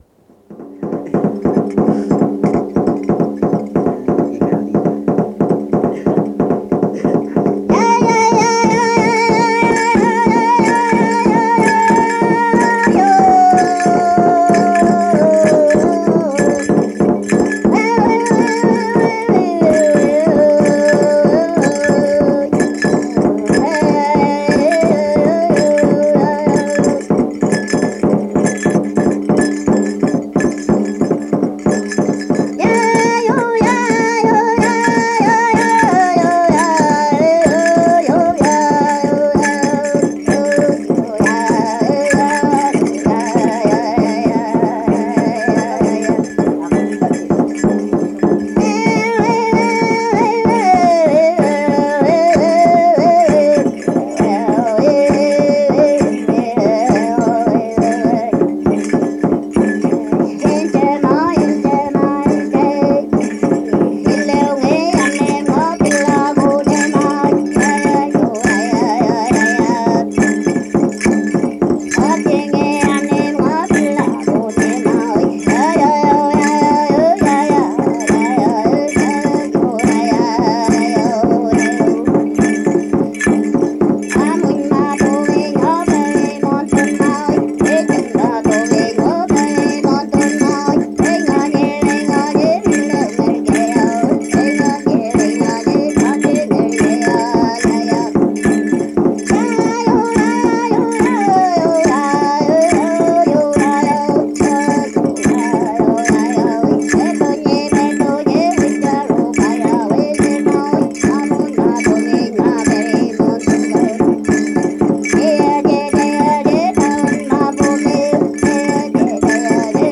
Música mapuche (Comunidad Quetrahue, Lumaco)
Música vocal
Música tradicional